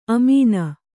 ♪ amīna